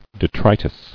[de·tri·tus]